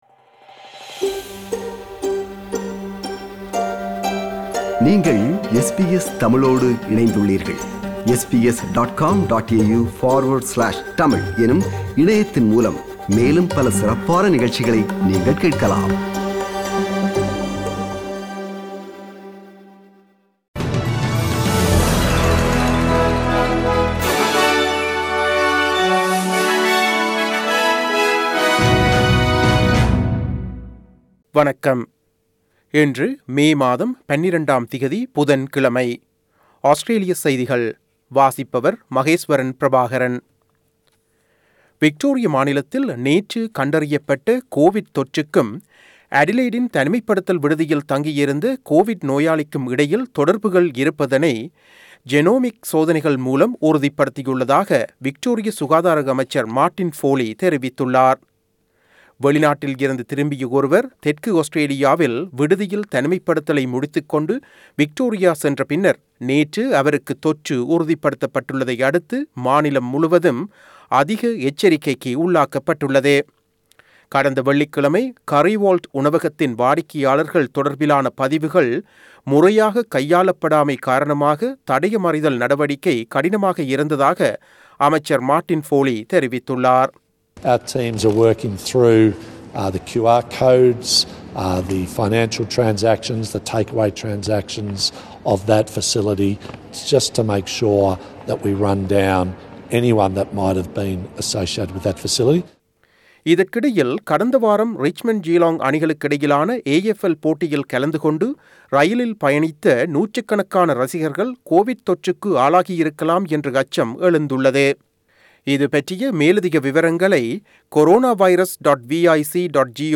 Australian news bulletin for Wednesday 12 May 2021.